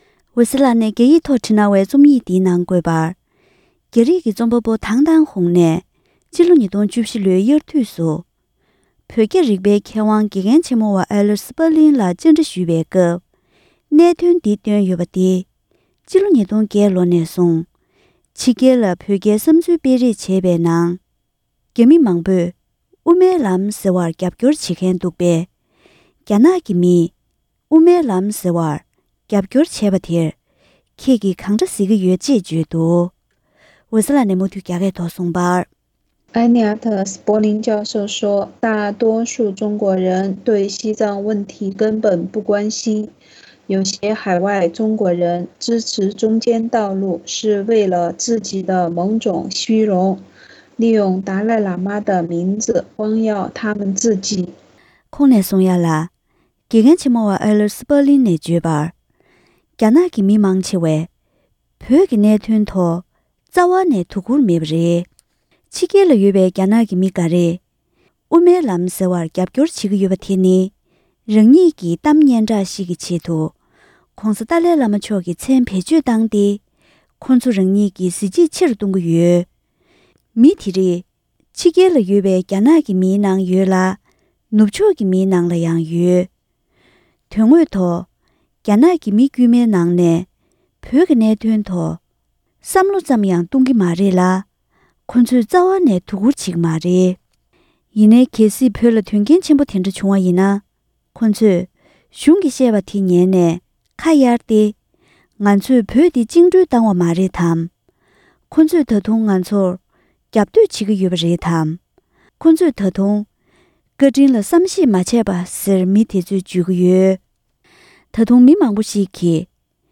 བོད་རྒྱའི་མཁས་པ་སྐུ་ཞབས་Elliot Sperlingལ་བཅར་འདྲི་ཞུས་པའི་དུམ་བུ་དགུ་པ།